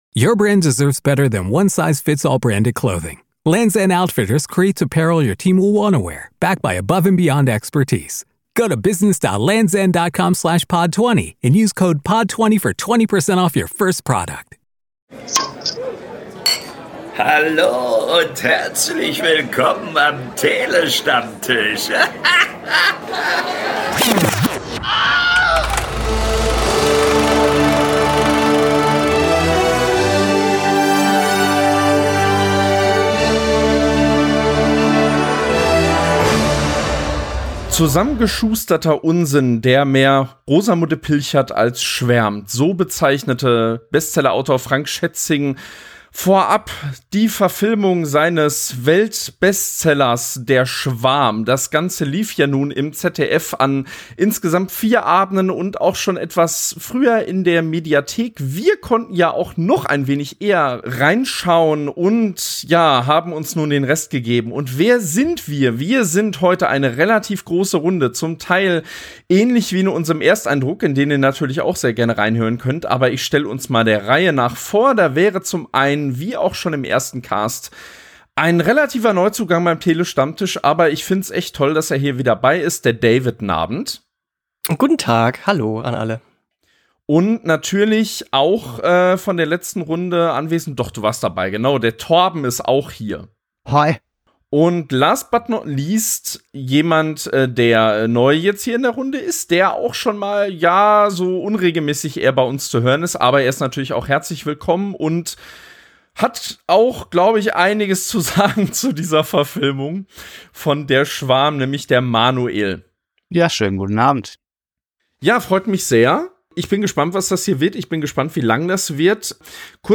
Nachdem auch wir in unserem Ersteindruck nach den ersten drei Episoden hörbar ernüchtert waren, haben wir nun die restlichen fünf Episoden nachgeholt und ziehen jetzt, passend dazu, in einer (viel zu) großen Runde, ebenfalls Bilanz.